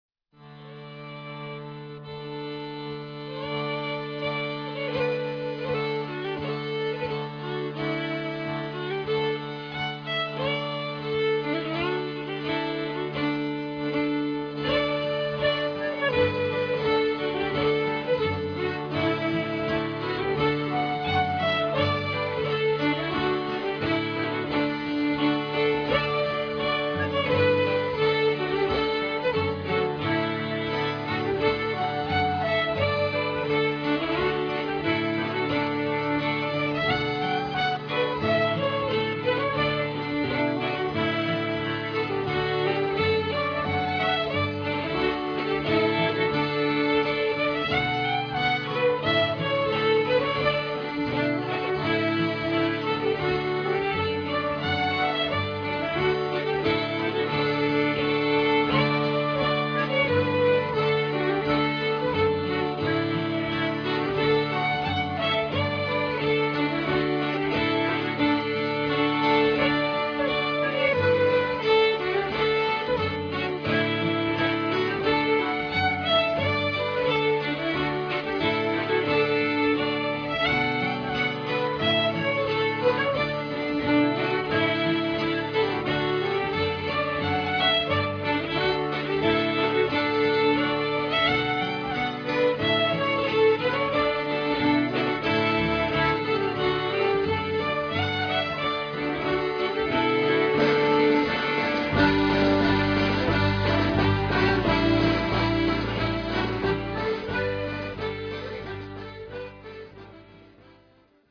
"Global beat ceilidh" - The Times Metro